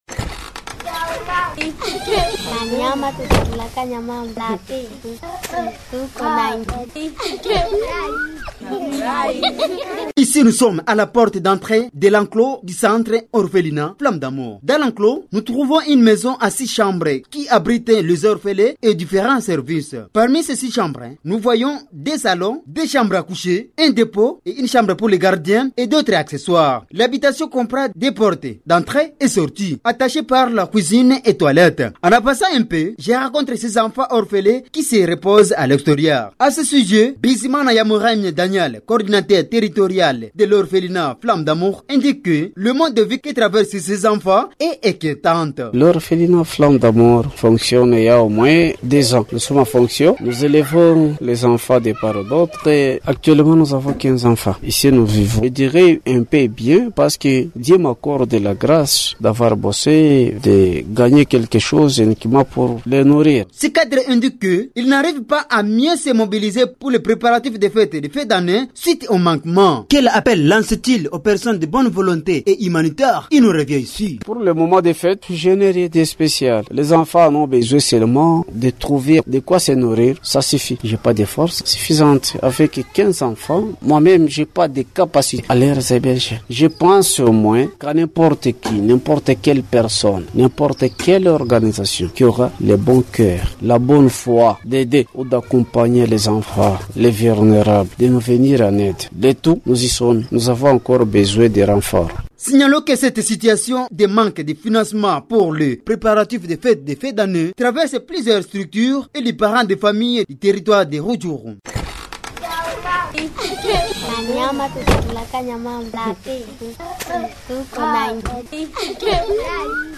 Dans une interview accordée à Habari za mahali